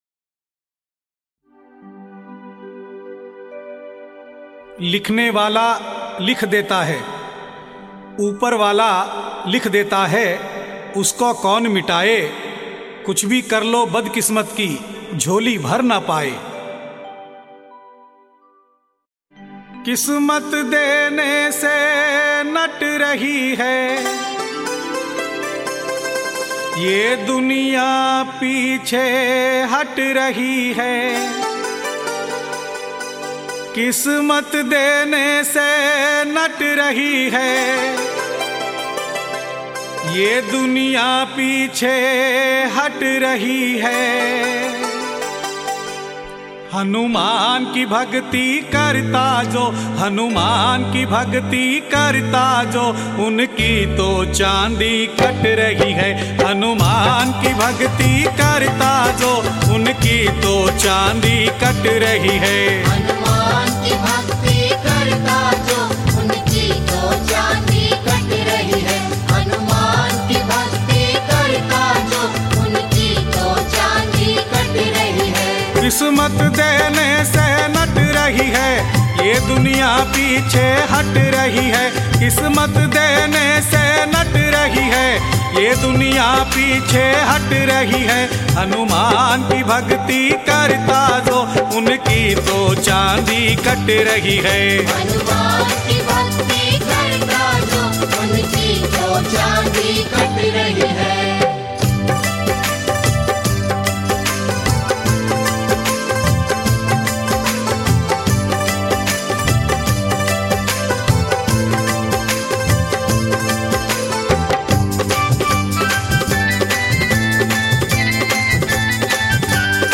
Rajasthani Songs
Balaji Bhajan